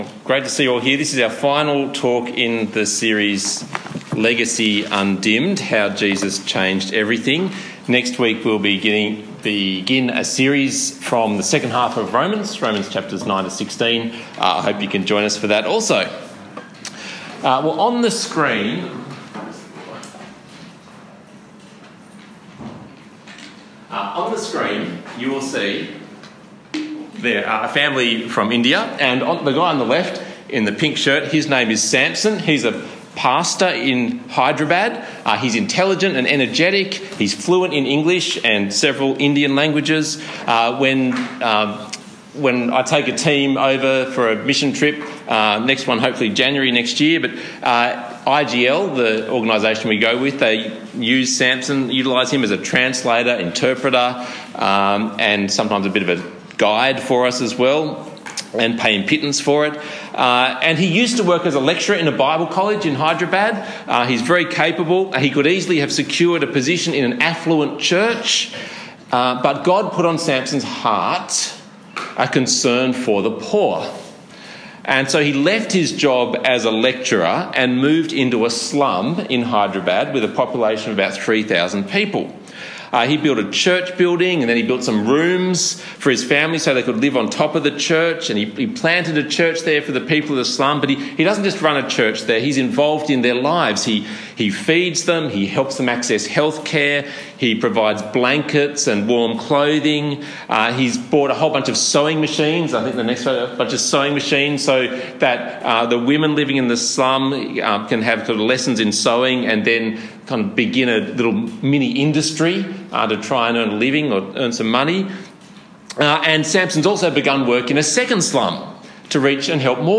Passage: Luke 10:25-37 Talk Type: Bible Talk